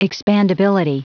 Prononciation du mot expandability en anglais (fichier audio)
Prononciation du mot : expandability